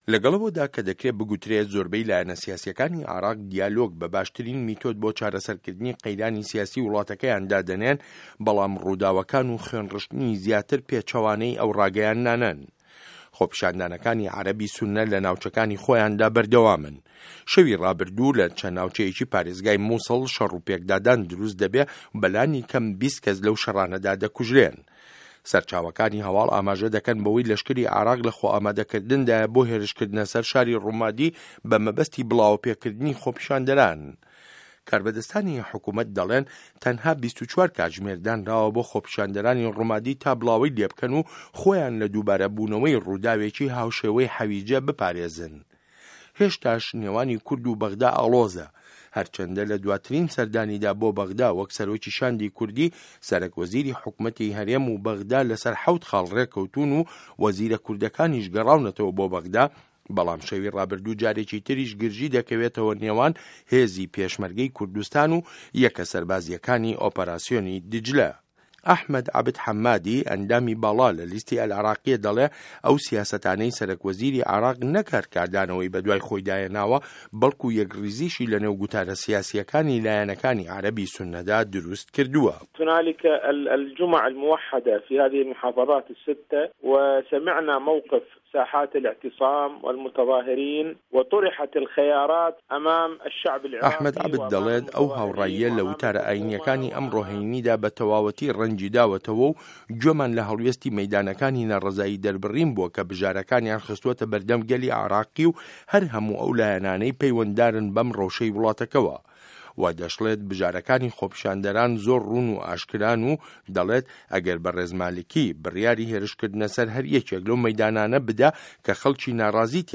ڕاپۆرتی کێن ئه‌وانه‌ی عێراق به‌ ئاقاری وێرانبوندا ده‌به‌ن